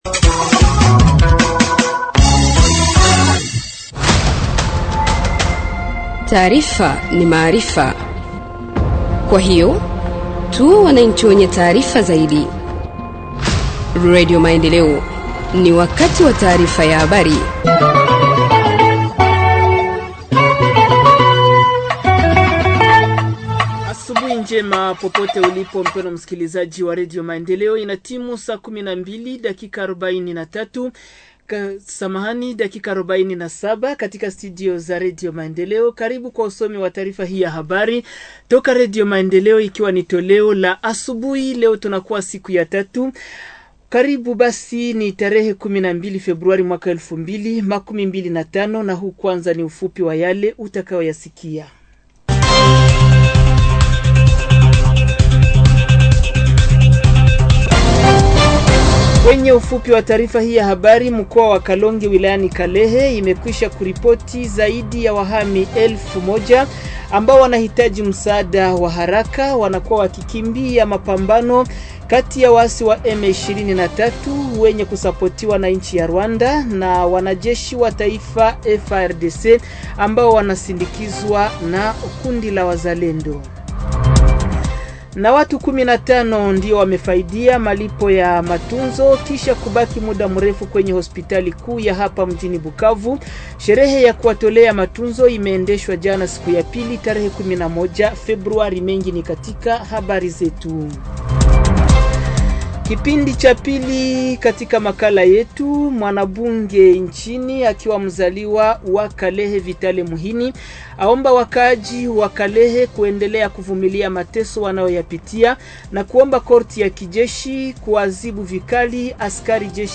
Journal en swahili du 12 février 2025 – Radio Maendeleo